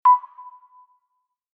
LAPUTA_alert.mp3